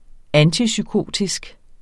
Udtale [ ˈanti- ]